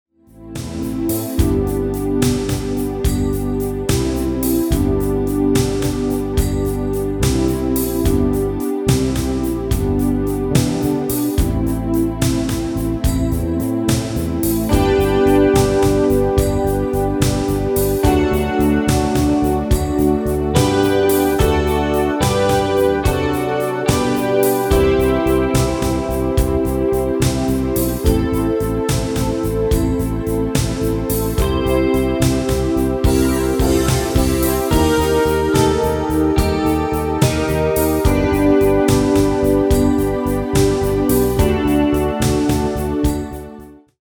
MP3-orkestband Euro 5.75